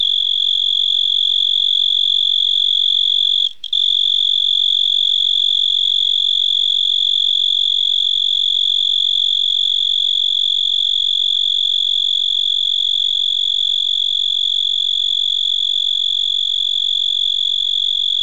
Pine tree cricket
Song
The song is a continuous, soft trill consisting of 45 pulses per second at 3.5 kHz at 77° F (25° C).
pine_tree_cricket_01.mp3